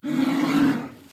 PixelPerfectionCE/assets/minecraft/sounds/mob/polarbear/warning1.ogg at mc116